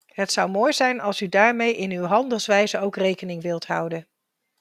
NL
female